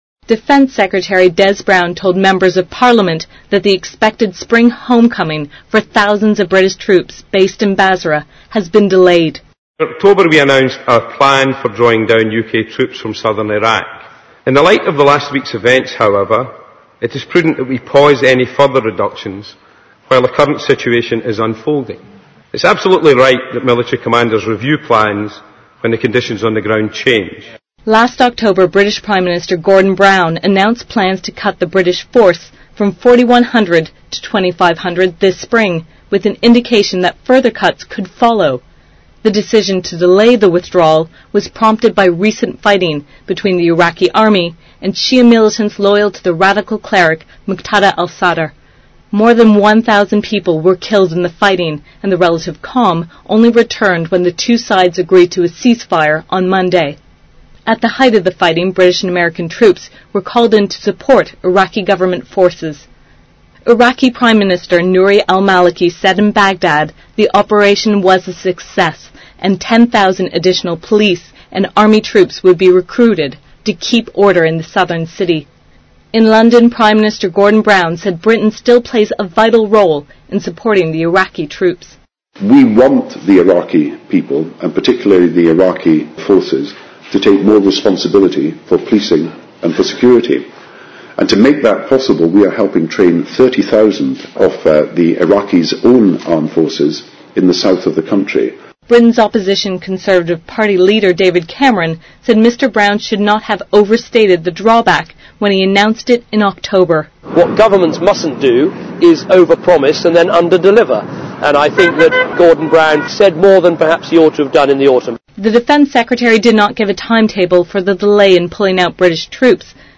英宣布推迟削减驻伊南部军队计划|英语新闻听力